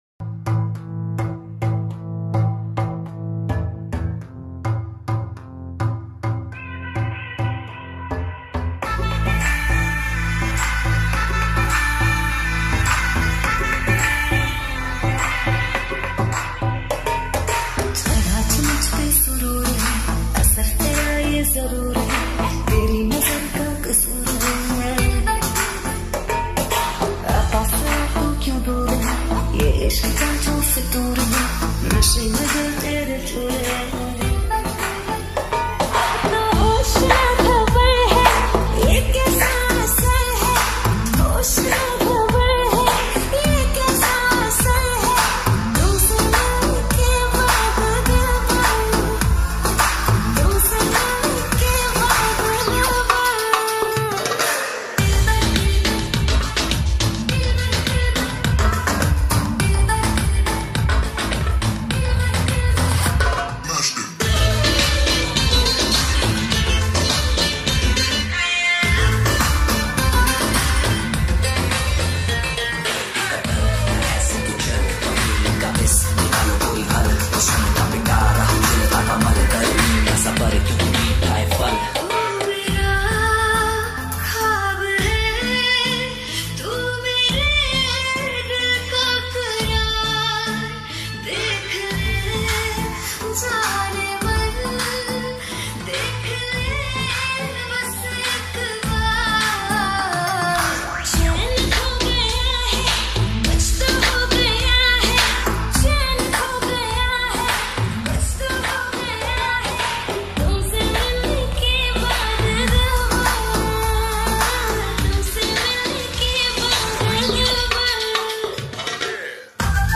8D MIX New Song